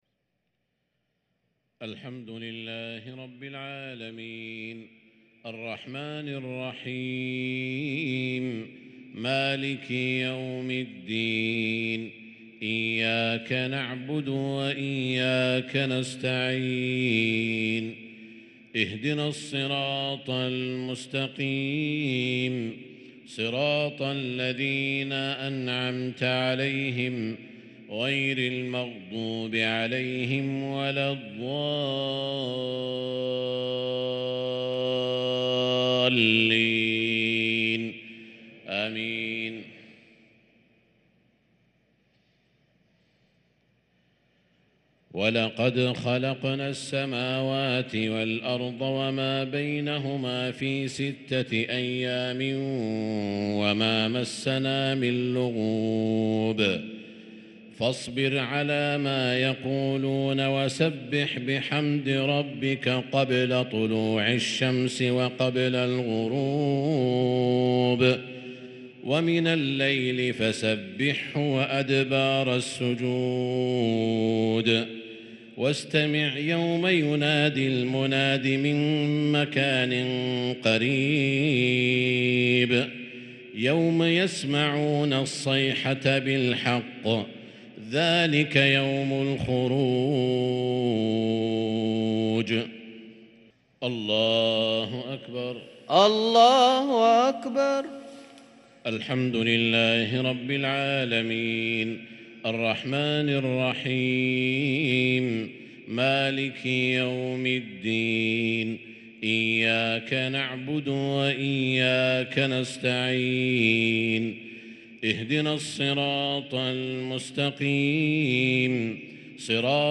صلاة المغرب للقارئ سعود الشريم 9 ربيع الأول 1444 هـ
تِلَاوَات الْحَرَمَيْن .